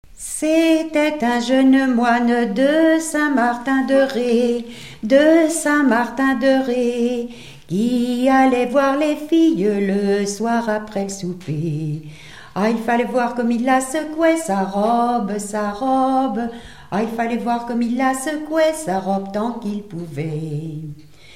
Genre laisse
Chanson / témoignage
Pièce musicale inédite